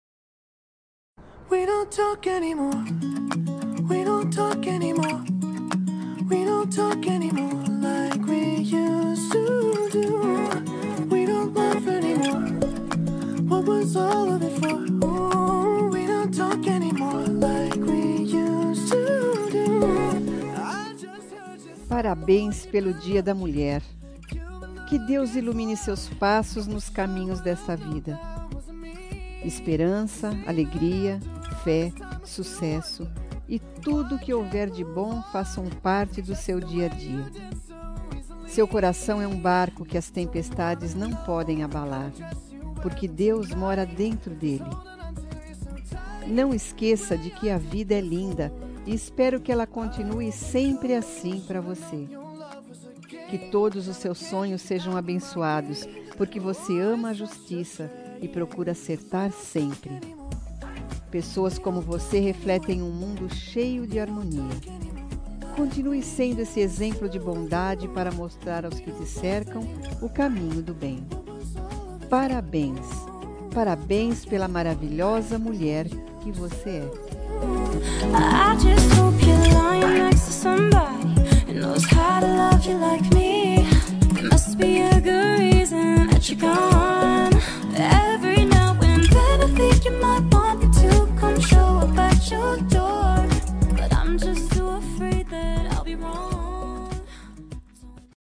Dia das Mulheres Neutra – Voz Feminina – Cód: 52670